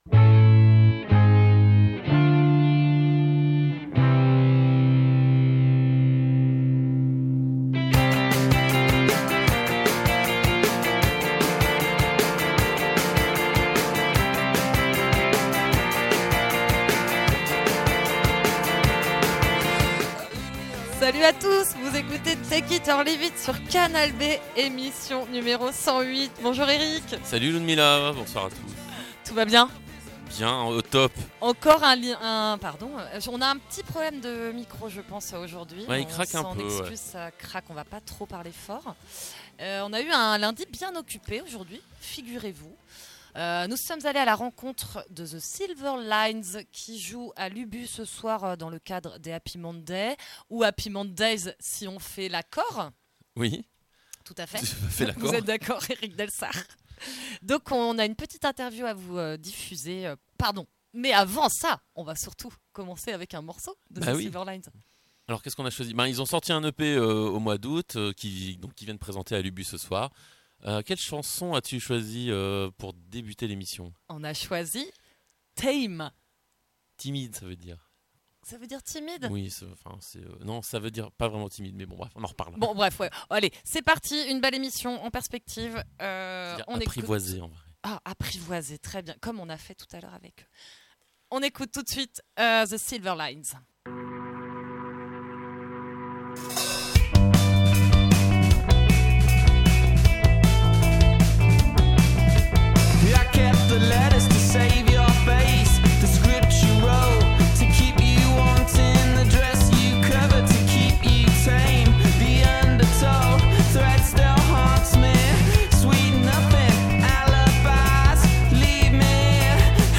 #108 - Silver Lines en interview